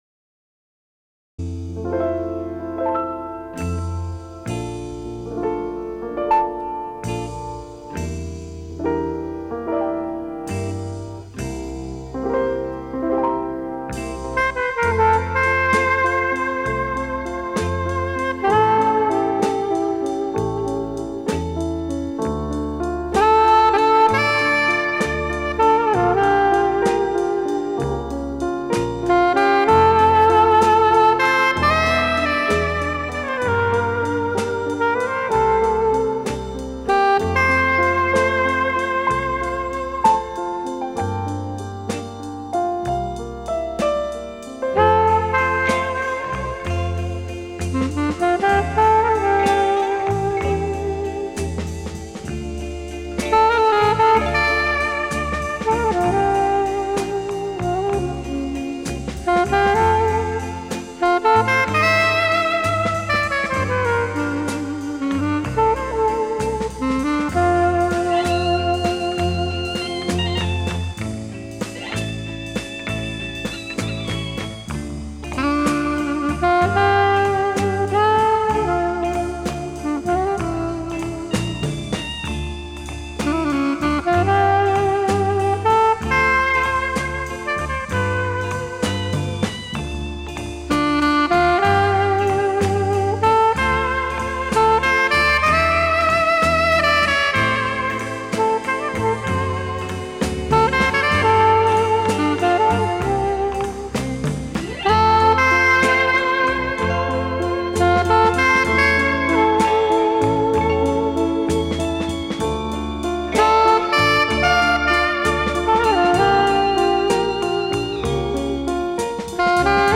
Жанр: Pop, Folk, World, & Country